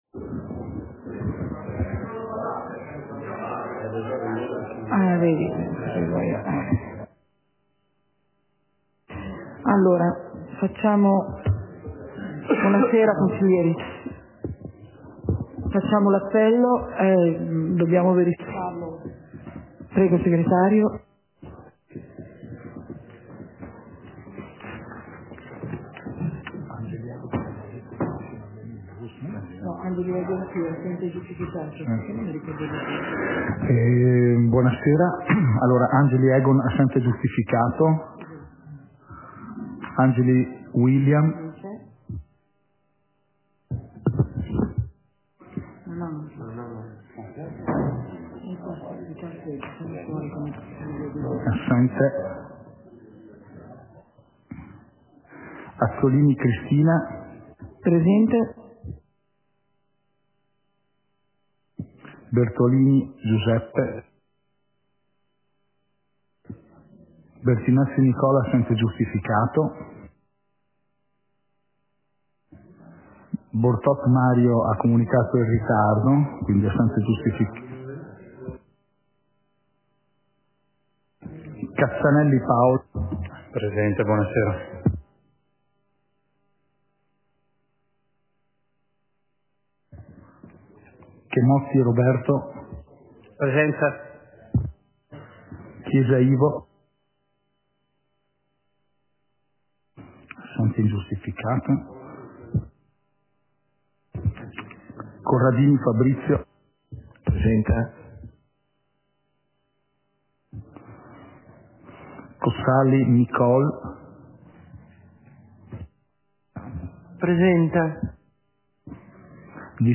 Seduta del consiglio comunale - 08.03.2023